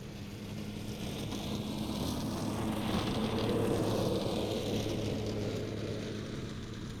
Zero Emission Subjective Noise Event Audio File (WAV)